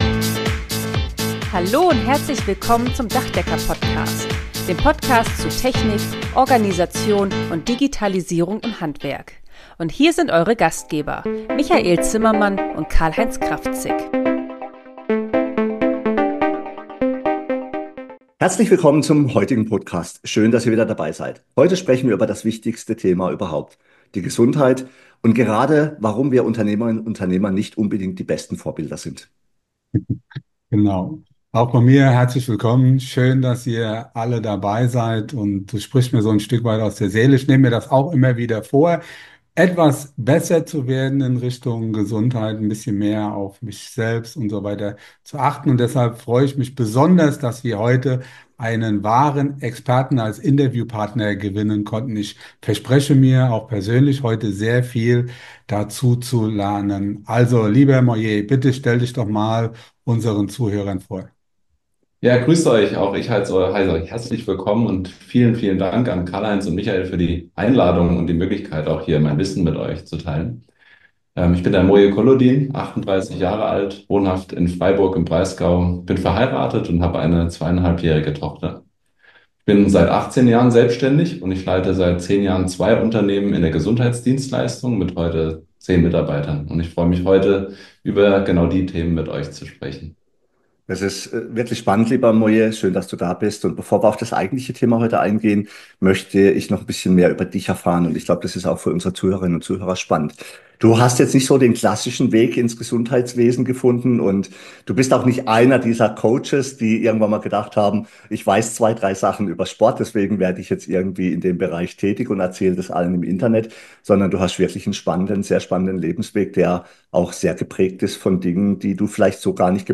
Gesundheit im Fokus – Interview